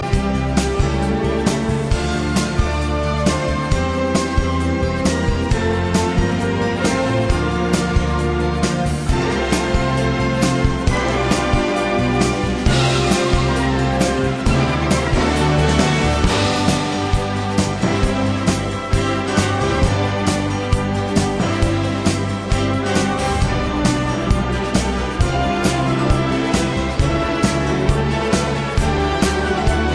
Startseite » sheet music Full Score music